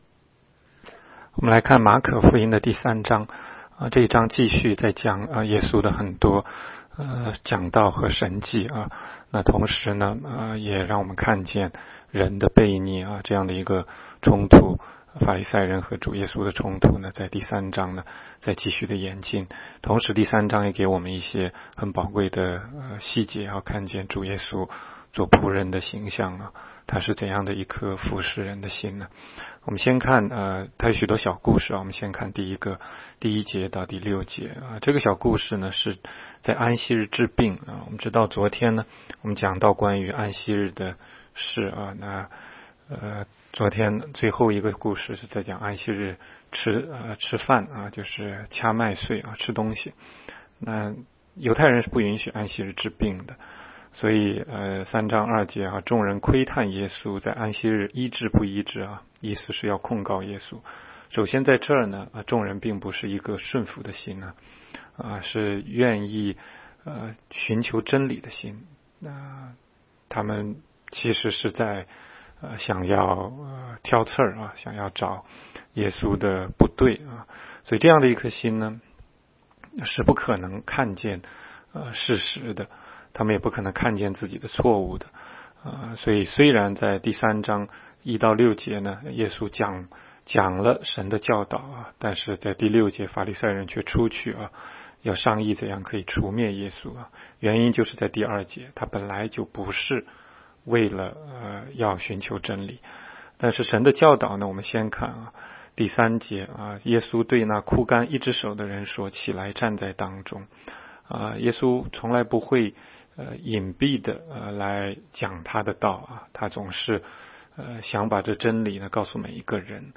16街讲道录音 - 每日读经-《马可福音》3章